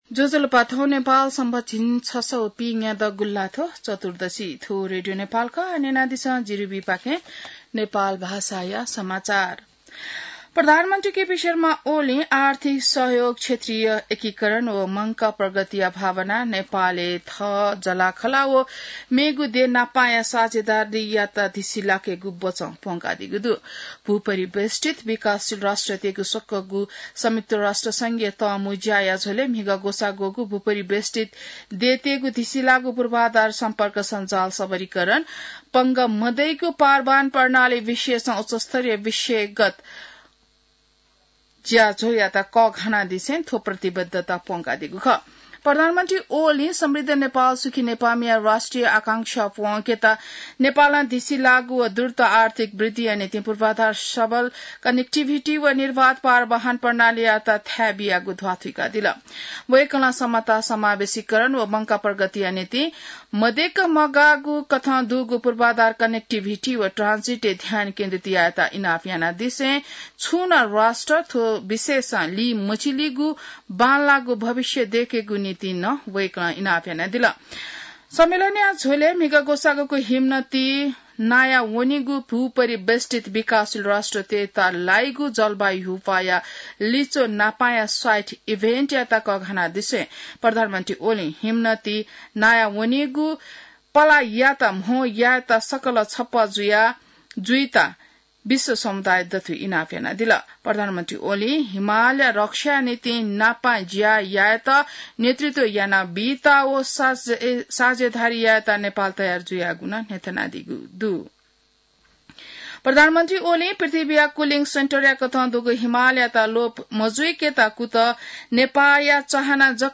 नेपाल भाषामा समाचार : २३ साउन , २०८२